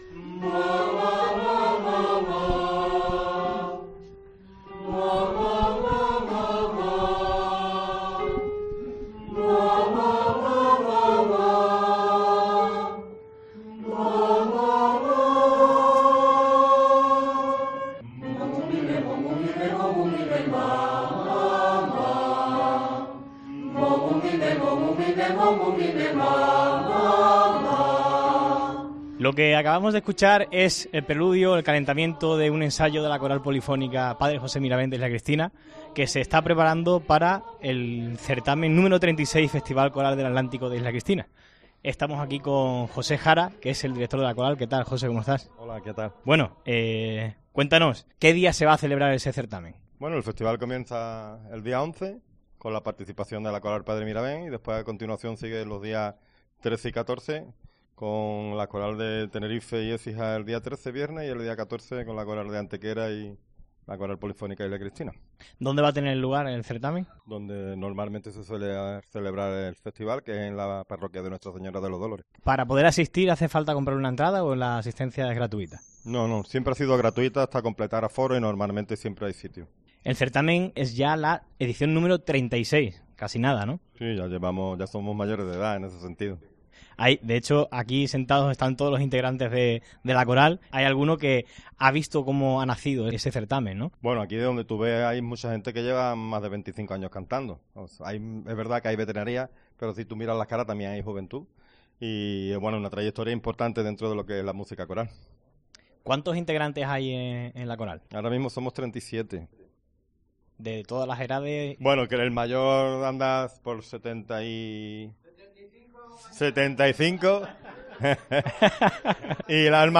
Entrevista | Coral Polifónica Padre José Miravent de Isla Cristina